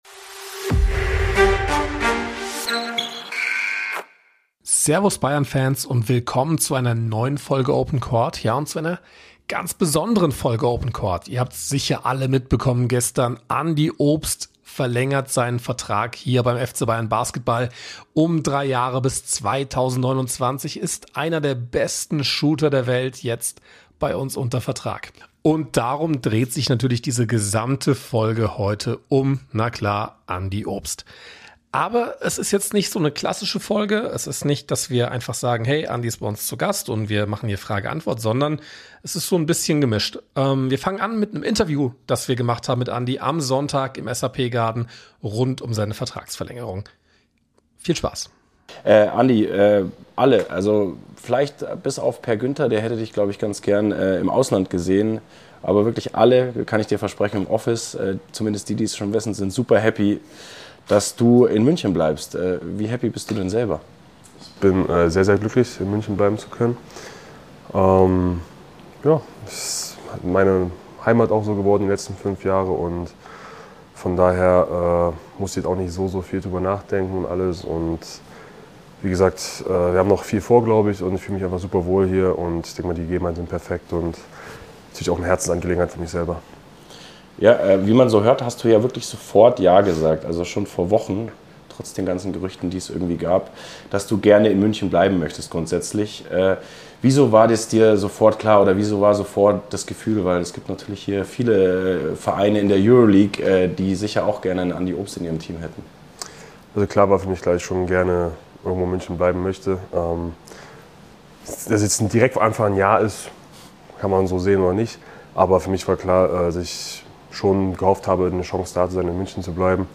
Beschreibung vor 1 Woche Ihr habt es alle mitbekommen, auch in den kommenden drei Jahren spielt einer der besten Shooter der Welt für die Bayern. Was Andi Obst selbst dazu sagt, hört Ihr in einem längeren Interview. Aber auch viele aus dem Kosmos des FC Bayern, von Uli Hoeneß über Jamal Musiala bis zu Giulia Gwinn, sprechen in dieser Spezial-Folge von OPEN COURT über den Coup der Bayern-Basketballer.